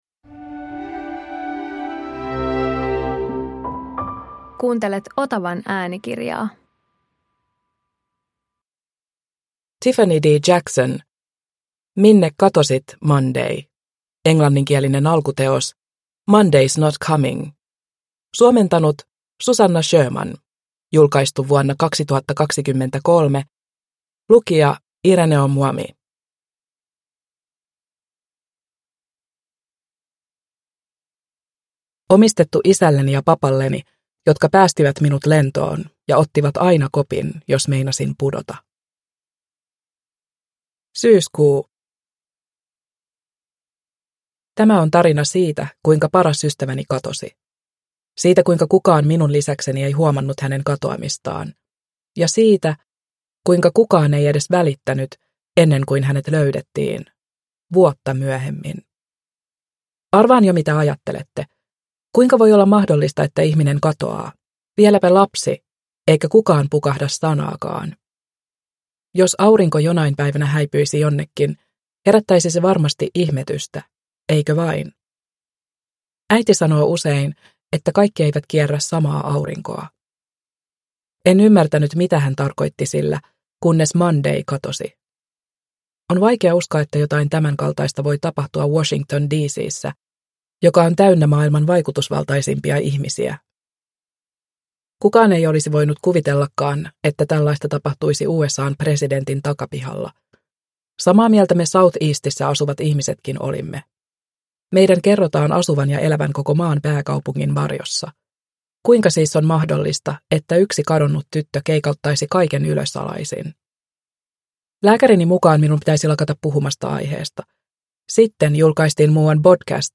Minne katosit, Monday? – Ljudbok – Laddas ner